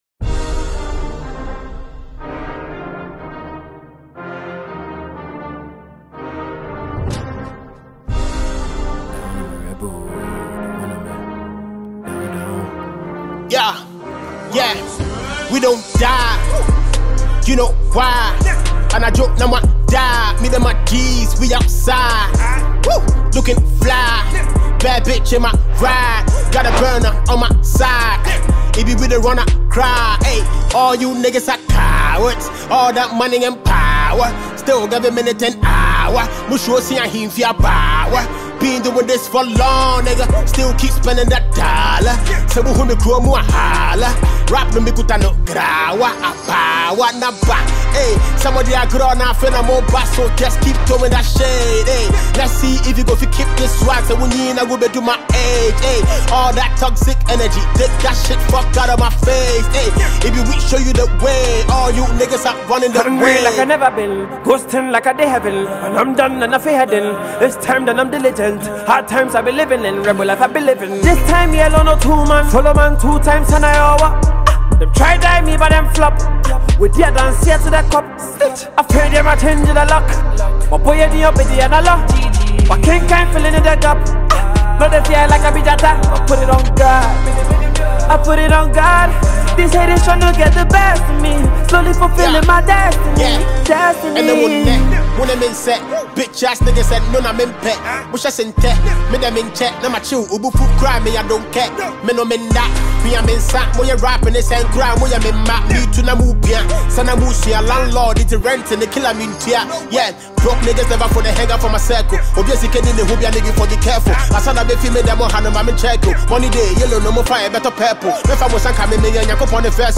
which is produced with crisp beats, catchy hooks